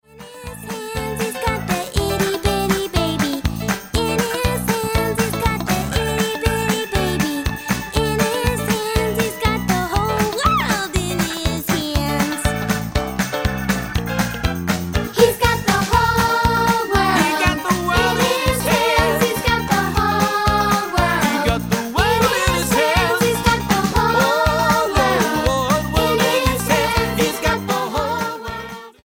STYLE: Childrens
Many of the original songs pop up again, eg, "Say To The Lord", "Praise God" and "Amen, Praise The Lord" to name just three, but they are interpreted in a fresh style, with new and exciting accompaniments and enthusiastic vocals.